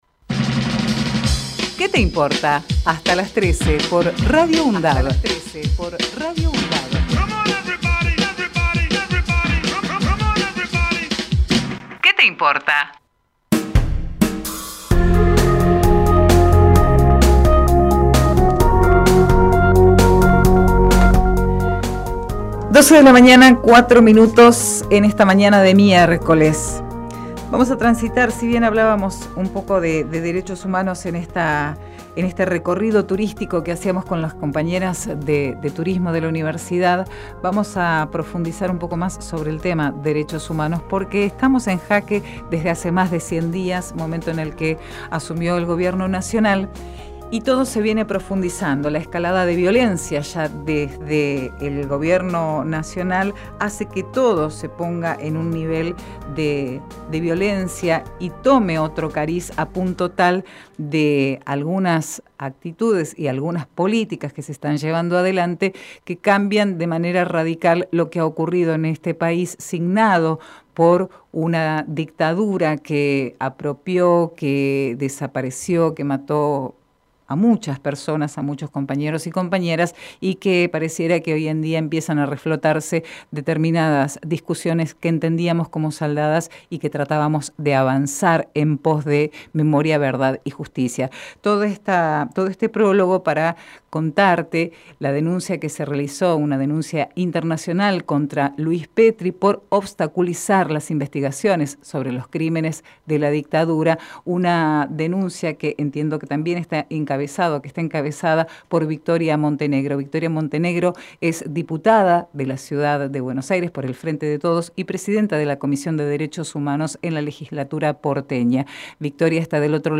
QUÉ TE IMPORTA - VICTORIA MONTENEGRO Texto de la nota: Compartimos entrevista realizada en "Que te Importa" con Victoria Montenegro.